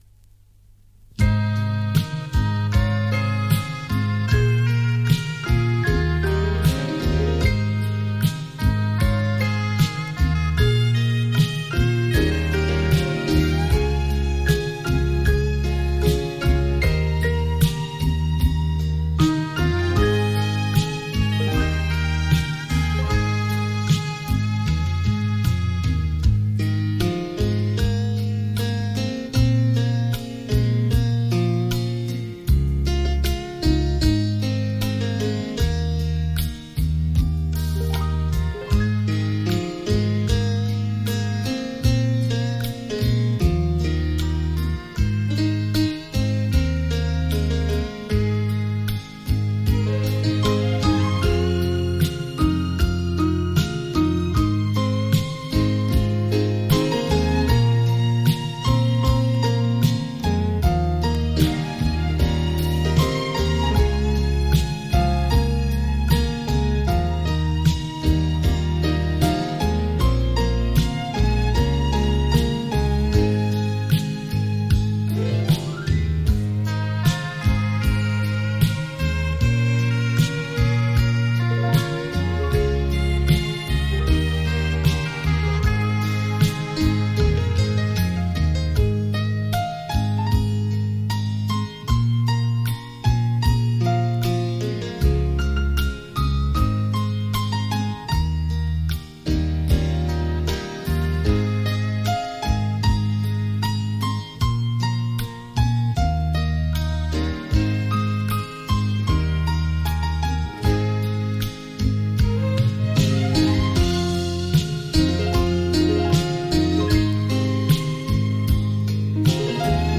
令人回味的音韵 仿佛回到过往的悠悠岁月